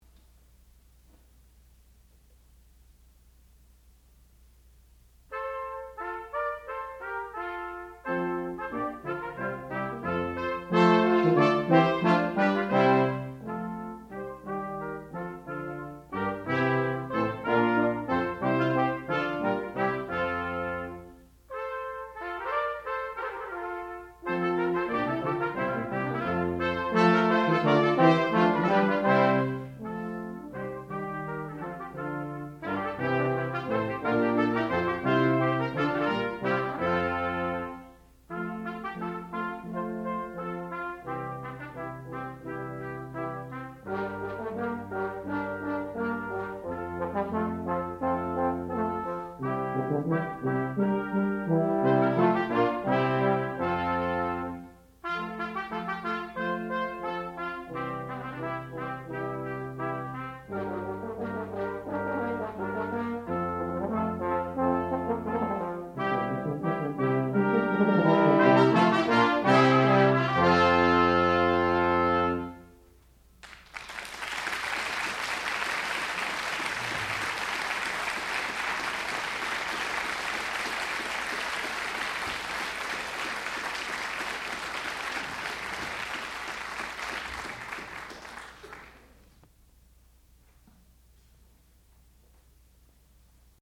classical music